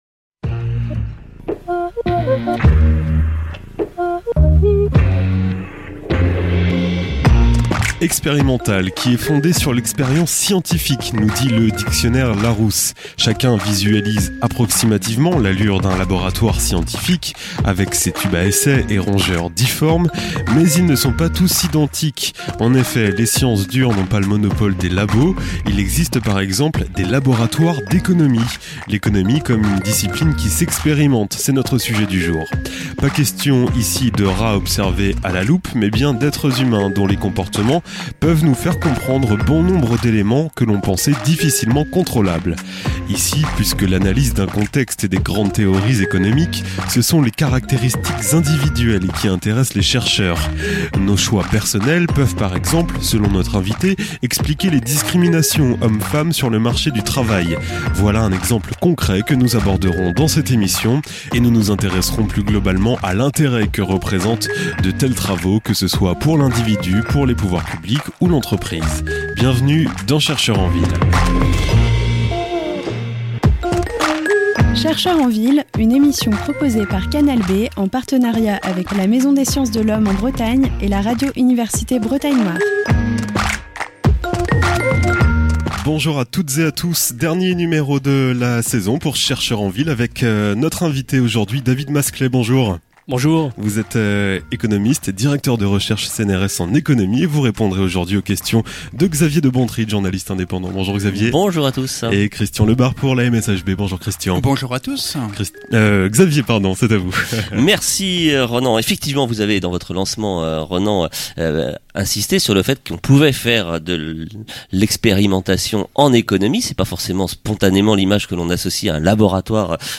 Dans la première partie de l'émission, ils évoquent les fondements et l'histoire de cette discipline, mais également ses applications, par exemple concernant la question de la discrimination à l'embauche des femmes. Le débat porte enfin sur les usages sociaux de l'économie expérimentale dans une perspective d'aide à la décision publique.